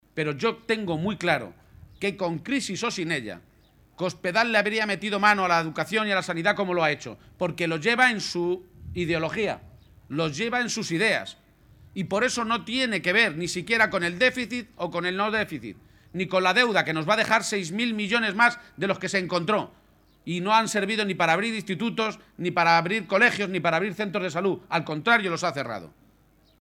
En una intervención previa a una comida con militantes y simpatizantes en Consuegra (Toledo), García-Page ha señalado que puede haber muchas personas que, de buena fe, piensen que pueden echar a Cospedal votando a unos u a otras formaciones políticas, “cuando la verdad es que solo hay un partido, el PSOE, que tiene capacidad para cambiar la presidencia de Castilla-La Mancha y más con la ley trampa que han fabricado y que podría provocar que miles de votos se fueran a la basura”.